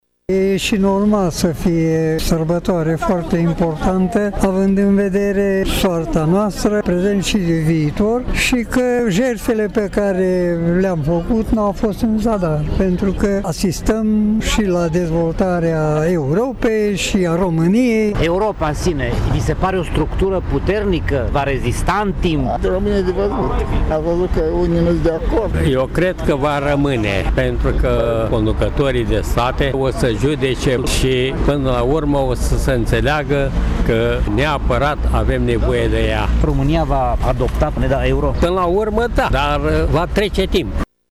Veteranii de război prezenți la ceremonie nu au uitat de trecut, și au spus că ei cred în viitorul UE doar dacă liderii statelor componente vor găsi calea dialogului: